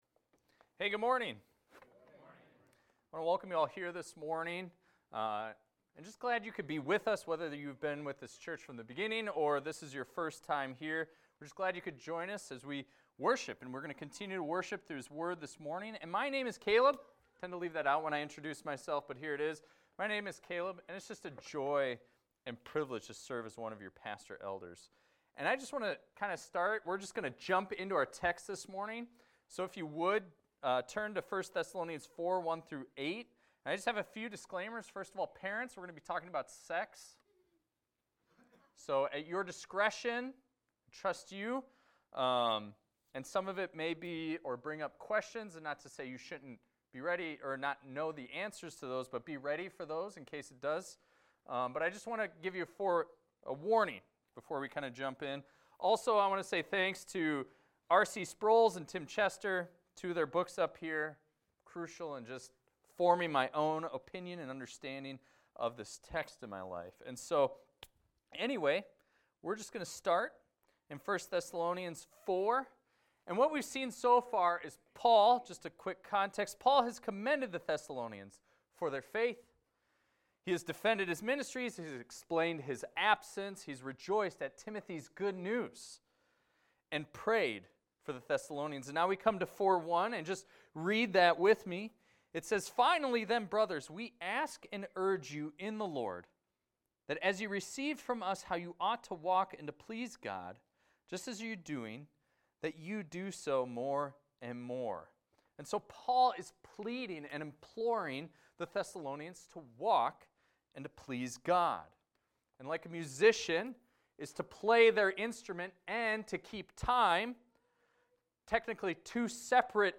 This is a recording of a sermon titled, "Living in Holiness."